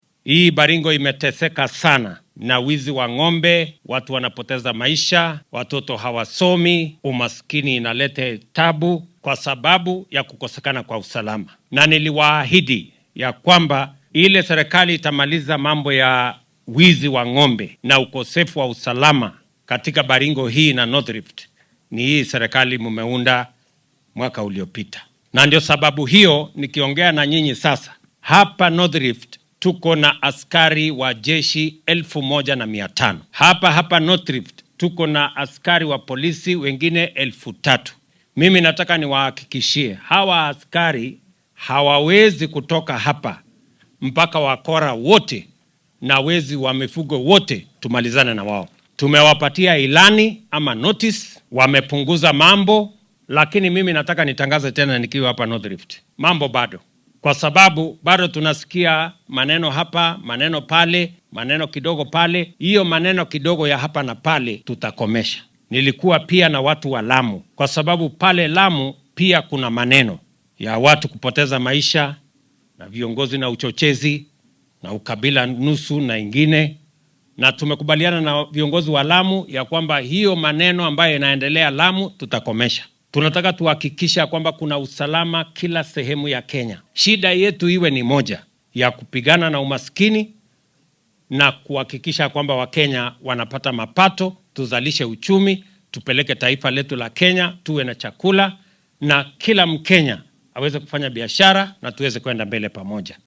DHAGEYSO:Madaxweynaha dalka oo ka hadlay xoojinta amniga Waqooyiga Rift Valley
Xilli uu ku sugnaa ismaamulka Baringo ayuu hoggaamiyaha Kenya carrabka ku adkeeyay in maamulkiisa uu ka dhabeyn doono ballanqaadkii ahaa ciribtirka falalka burcadnimo iyo dhaca xoolaha ee saameeyay gobolkaasi.